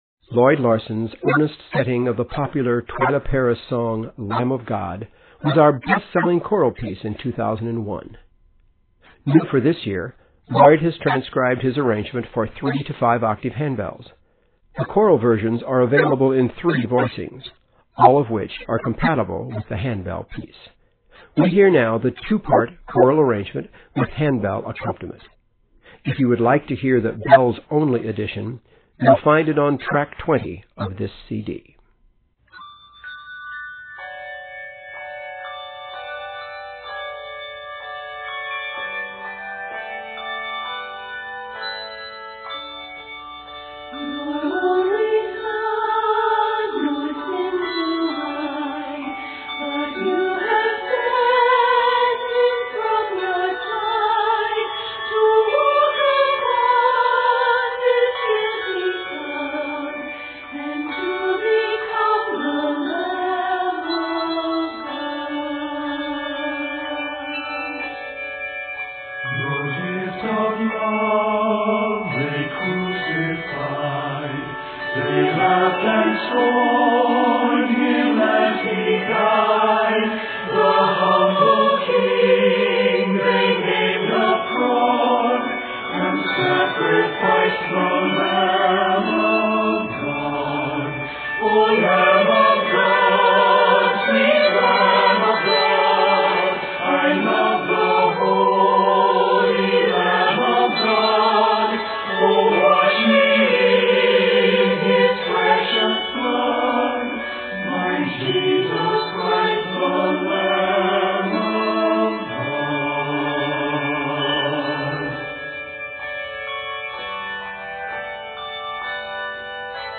3-5 octave handbells